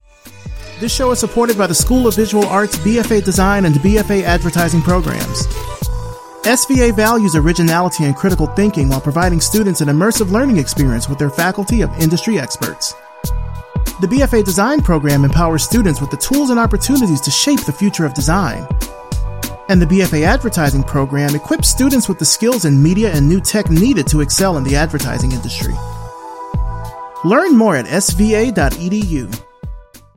Your Friendly, Neighborhood, Guy Next Door Narrator
This is a radio ad for SVA's BFA Design and BFA Advertising Programs.
African-American, U.S. Southern, French
Young Adult